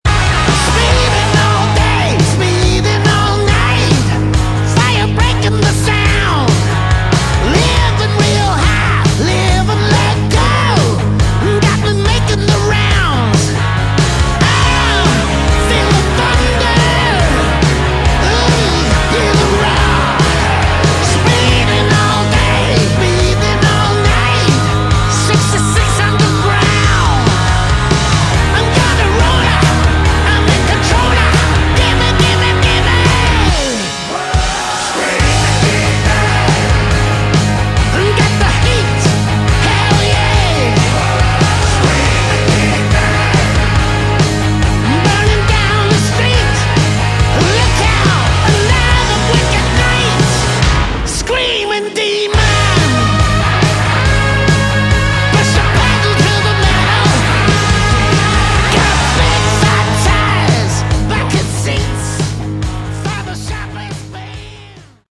Category: Hard Rock
vocals
rhythm guitar
lead guitar
bass
drums